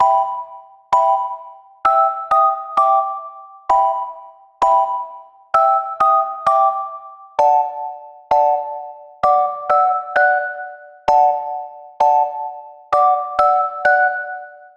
Tag: 65 bpm Weird Loops Synth Loops 2.49 MB wav Key : E